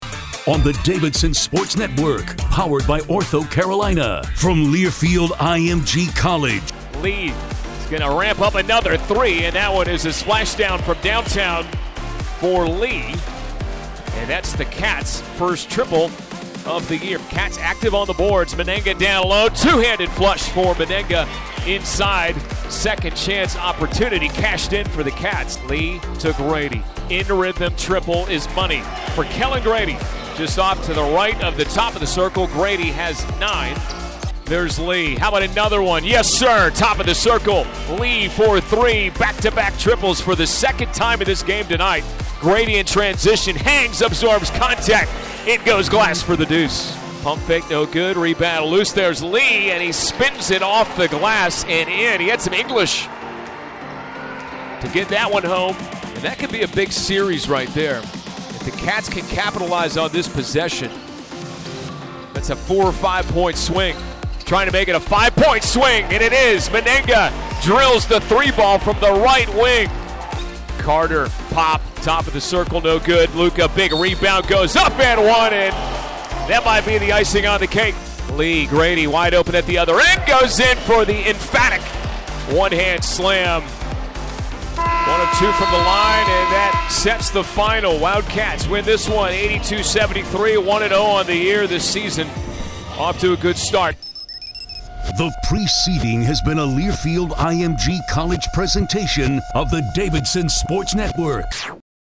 Radio Highlights | Photo Gallery